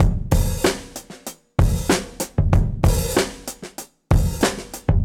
Index of /musicradar/dusty-funk-samples/Beats/95bpm
DF_BeatA_95-04.wav